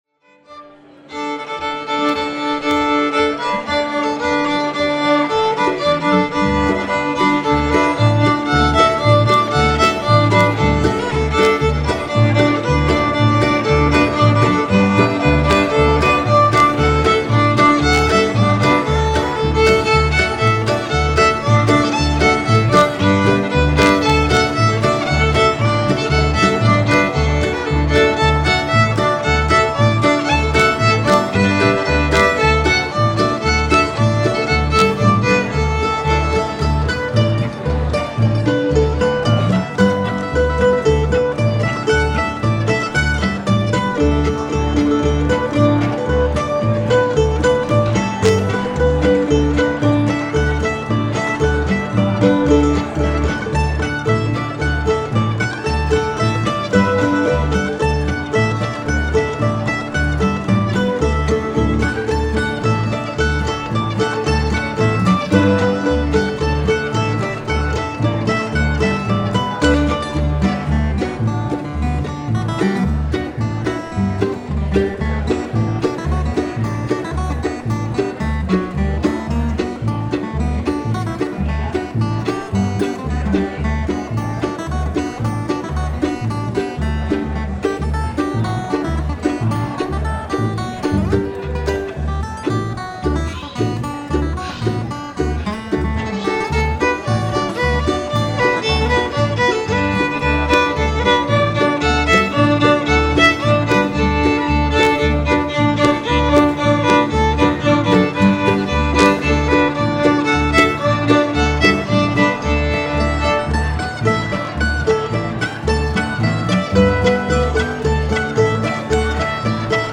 I could use some help figuring out the cause of a noise problem that cropped up last weekend when my bluegrass band performed at a local indoor farmers market.
The issue is a loud snapping noise that can be heard in some of the recordings at moments where the sound level produced by the singer or instrument is high. To me, the noise sounds electrical, like static or a short, but I'm no expert.
View attachment 21 Angelina Baker 2018-02-03.mp3 - noticeable in first 30 seconds over fiddle
And during the mando right after.